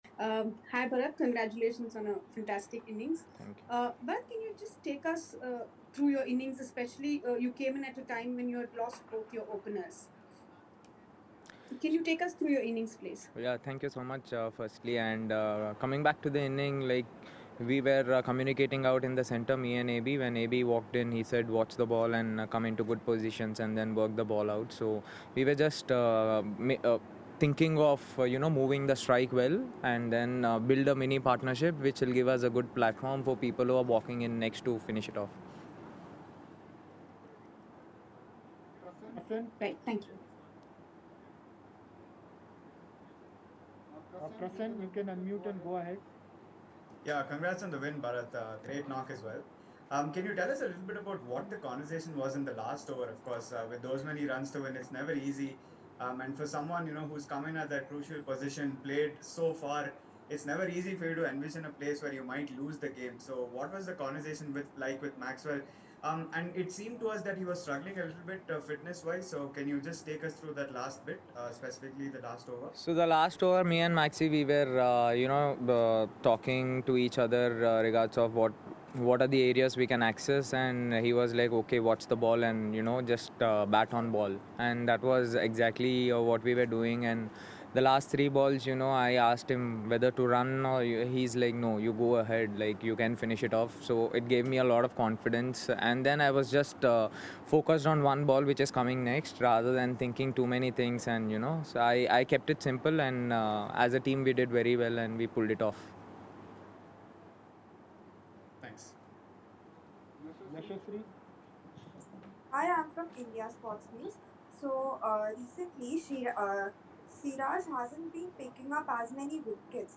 KS Bharat of Royal Challengers Bangalore addressed the media at the end of the game
KS Bharat of Royal Challengers Bangalore and Rishabh Pant of Delhi Capitals addressed the media at the end of the game.